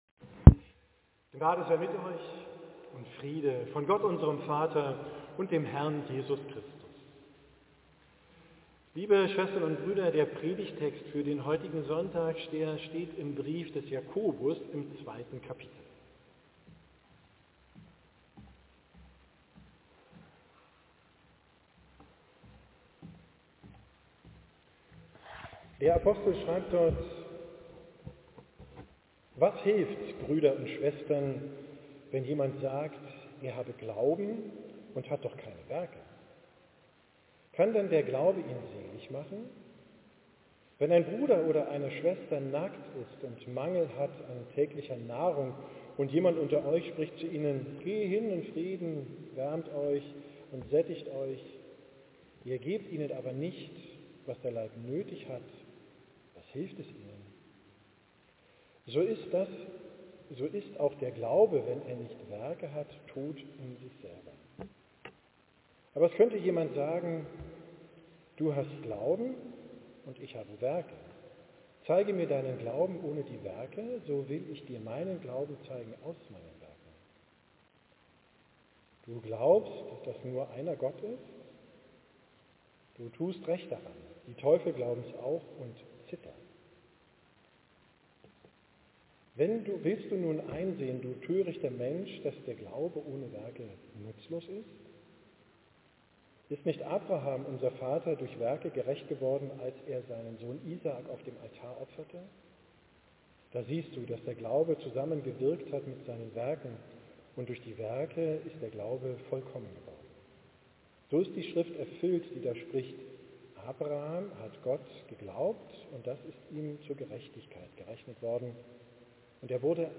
Predigt vom 18.
gehlaten in St. Petri Altona